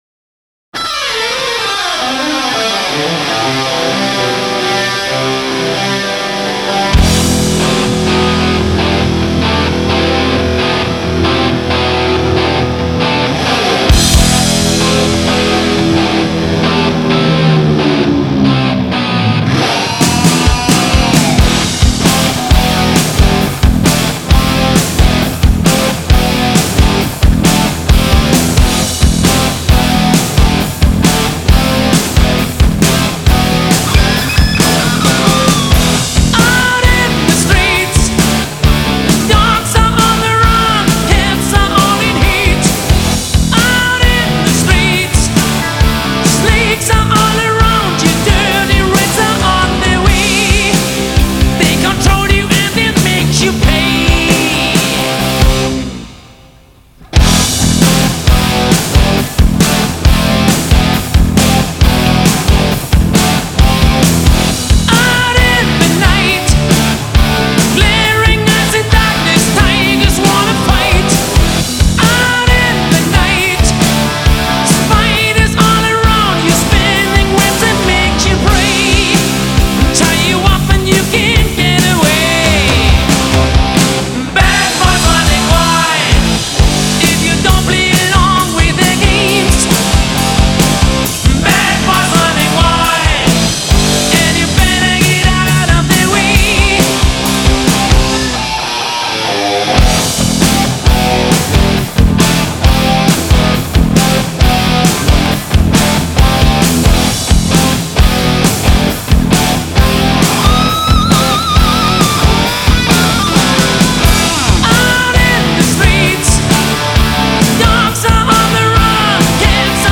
Hard Rock, Heavy Metal, Arena Rock